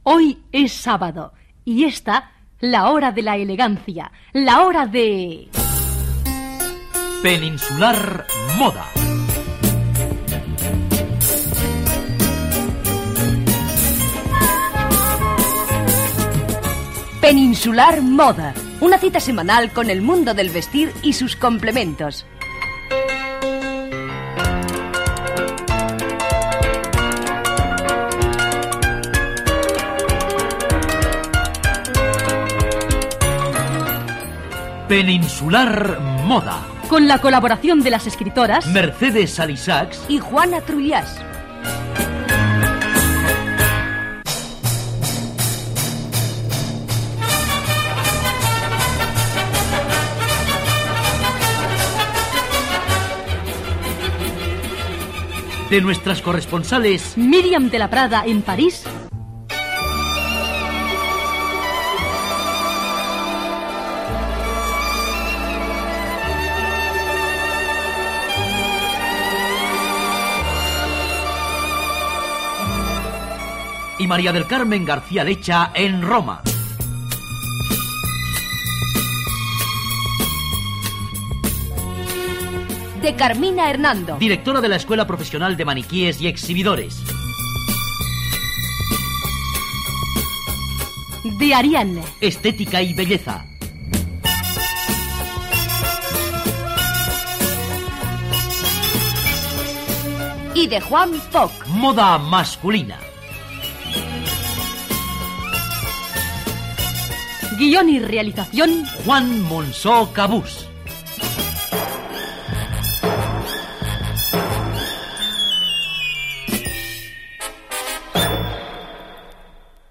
Careta del programa amb els noms de l'equip.